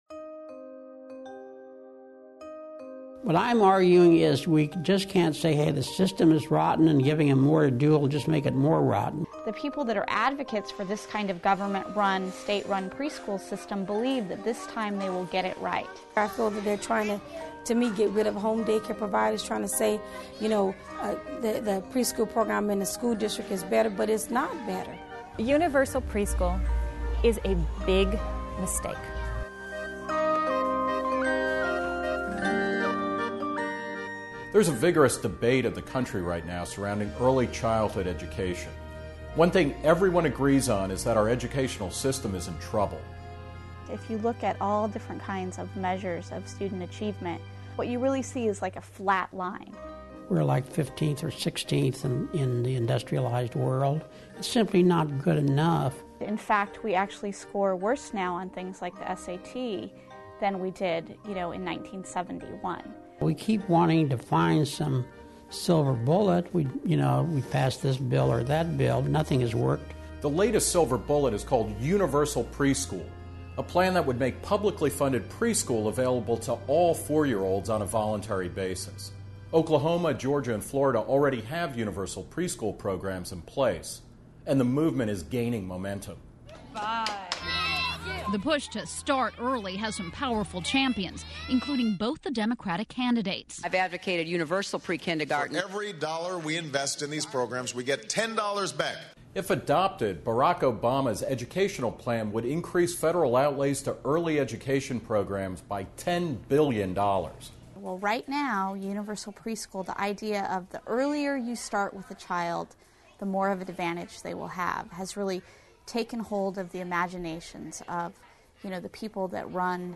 This 10-minute documentary is hosted by reason's Nick Gillespie.